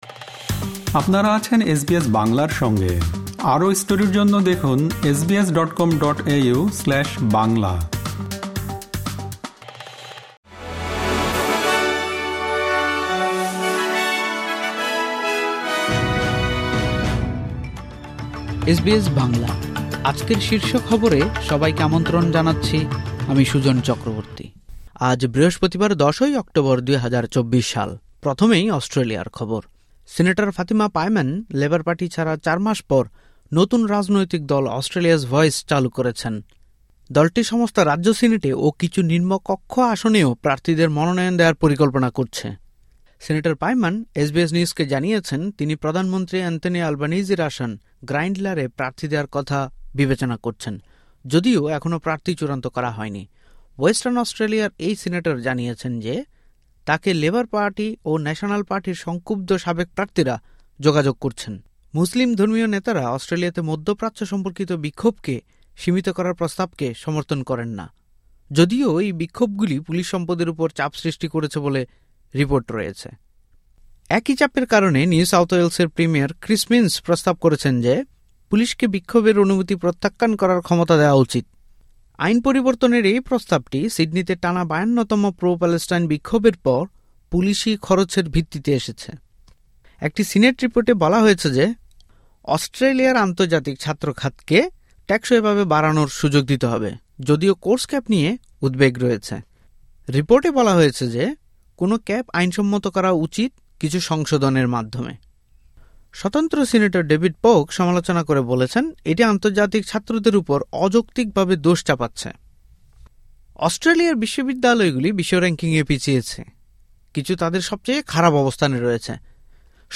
এসবিএস বাংলা শীর্ষ খবর: ১০ অক্টোবর, ২০২৪